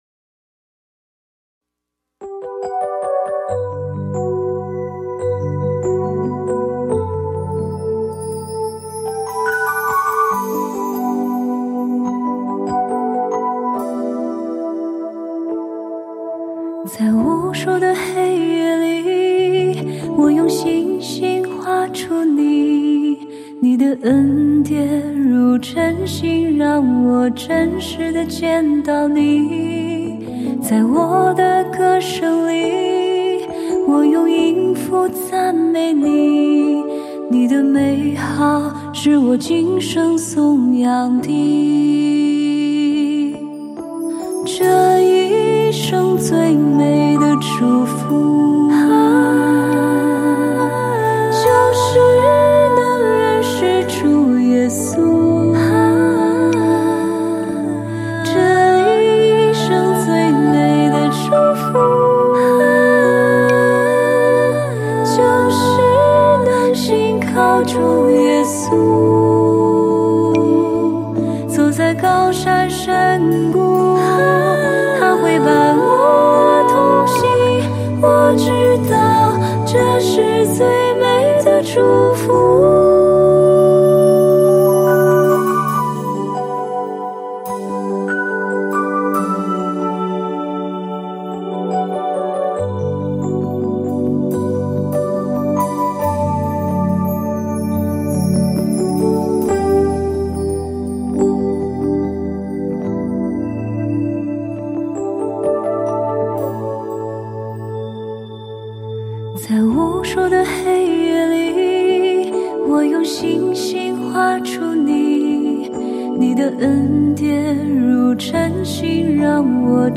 有些旋律，像林间不期而遇的溪流，初听只觉清澈悦耳，再听，却仿佛有光，照亮那些蒙尘的角落。
它不喧哗，不张扬，只安静地叩问：这一生，何谓最美的祝福？